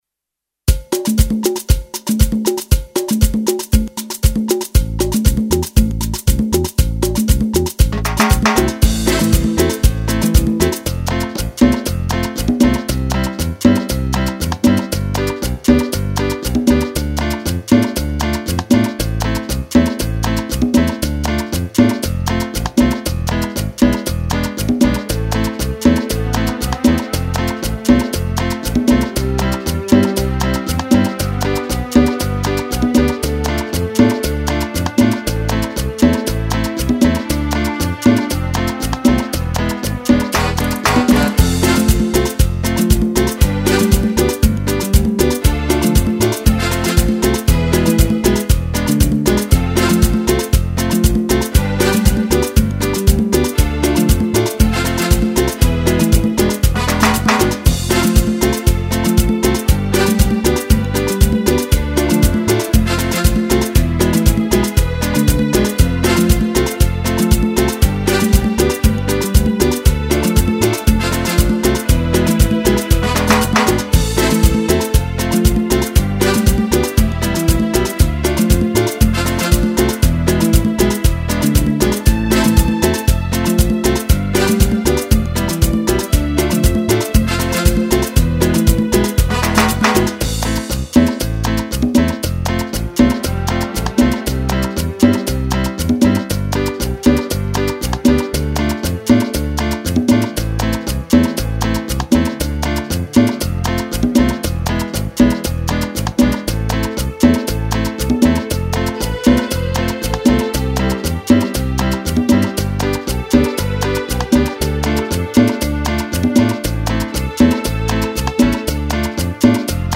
Canzoni e musiche da ballo